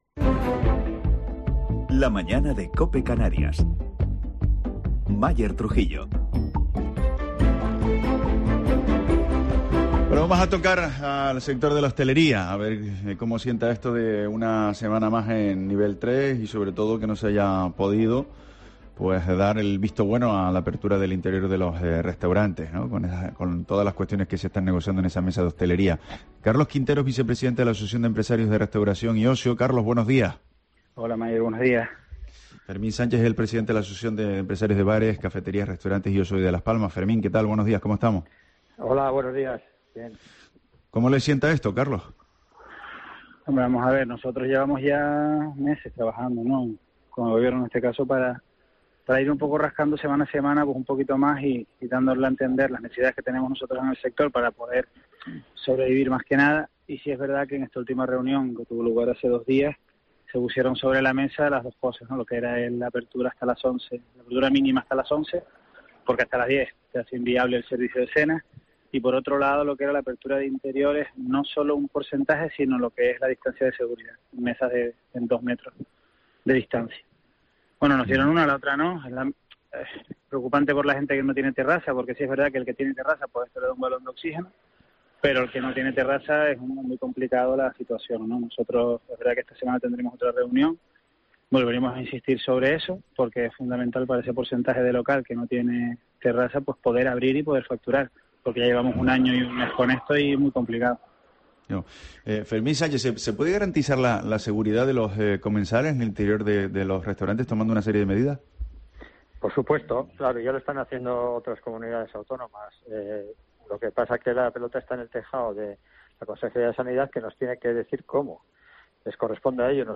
empresarios hosteleros, comentan las medidas para el sector